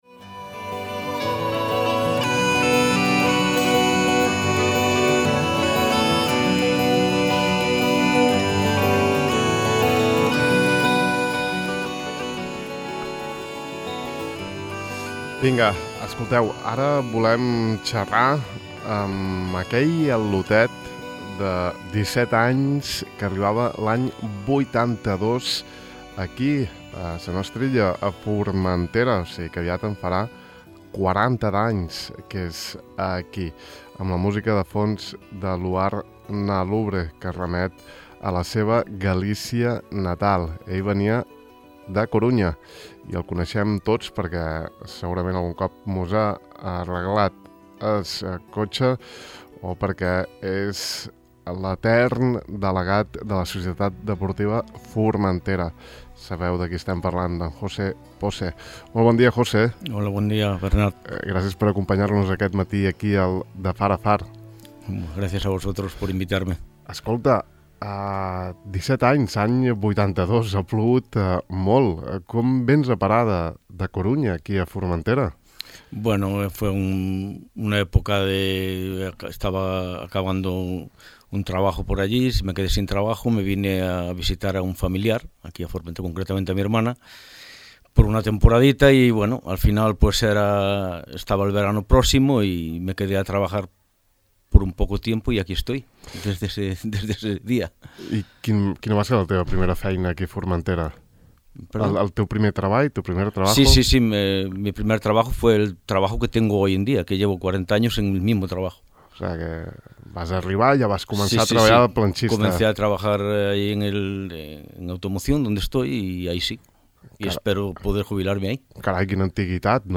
a qui hem entrevistat aquest matí en profunditat